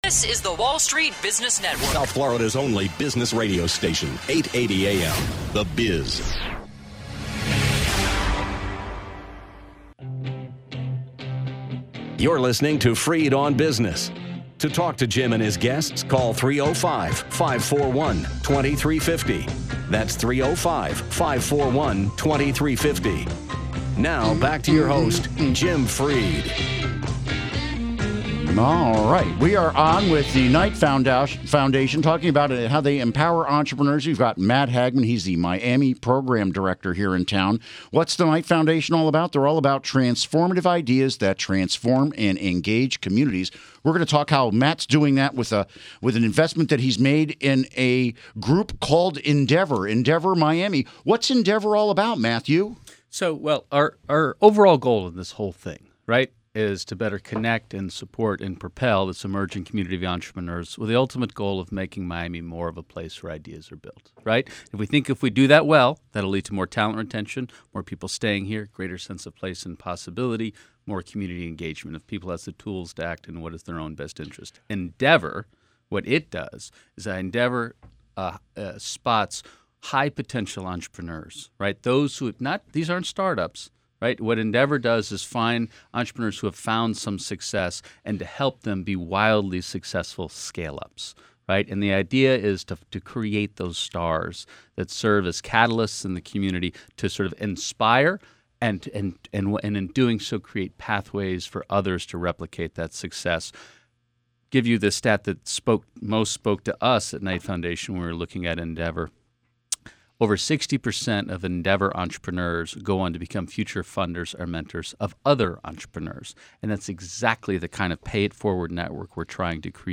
Interview Segment Episode 239: 11-14-13 (To download Part 1, right-click this link and select “Save Link As”.